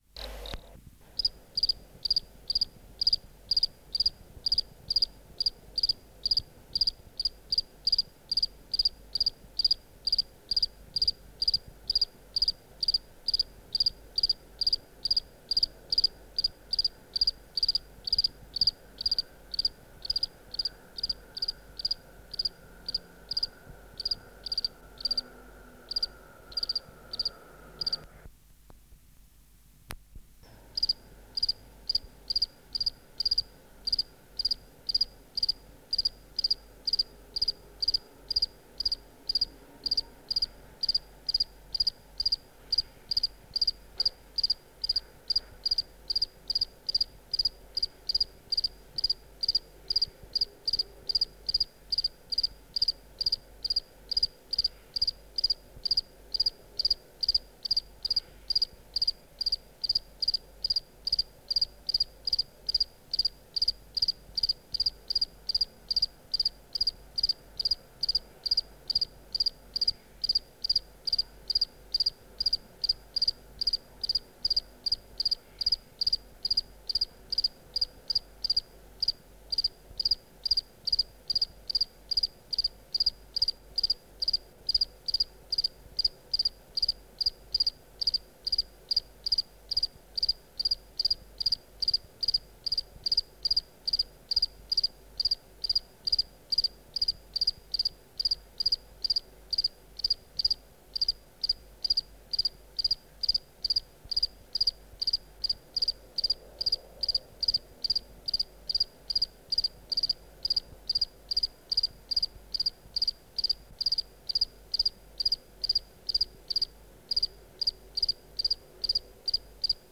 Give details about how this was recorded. Recording Location: BMNH Acoustic Laboratory Substrate/Cage: made through perforated zinc end of 'aquarium' cage Microphone & Power Supply: Grundig GRM1 Filter: Bennett Filter high pass filter set at 500Hz